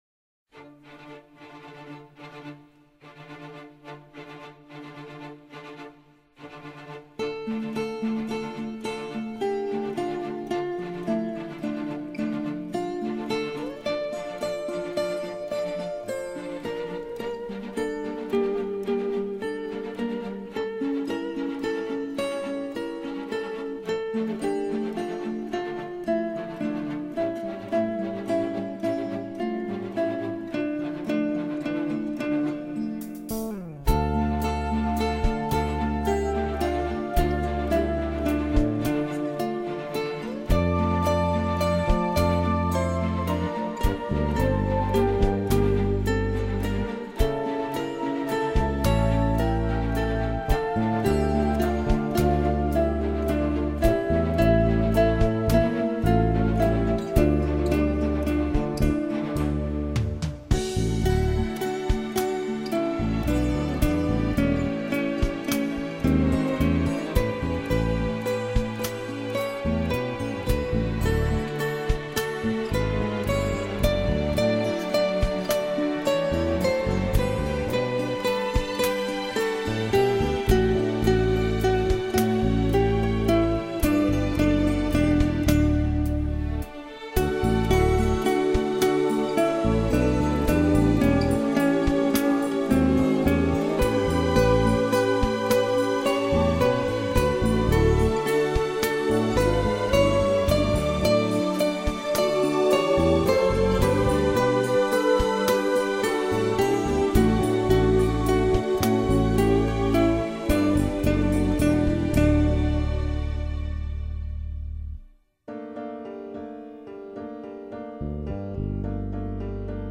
他以清新、明快的音乐风格，浪漫、华丽的表现手法，编写和录制了大量 的作品。